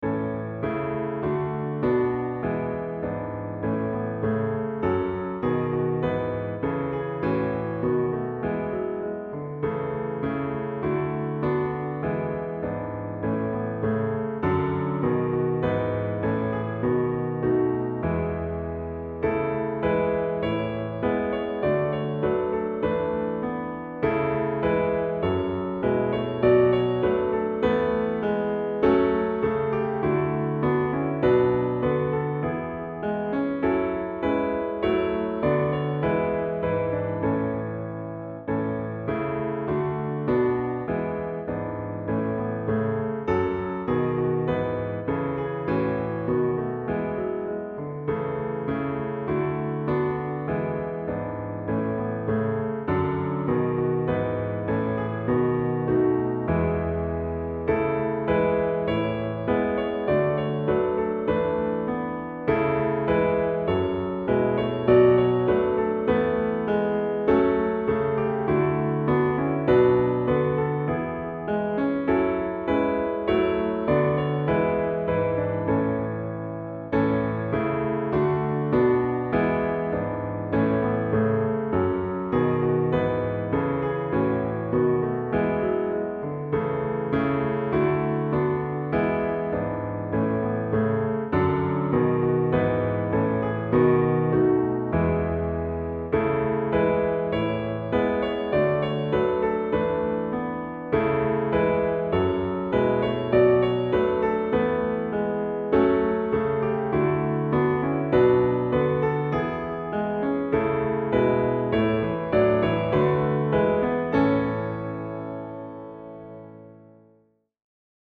WEISSE FLAGGEN accompaniment in A:
We especially love WEISSE FLAGGEN, a glad tune from 1741 which some congregations already know.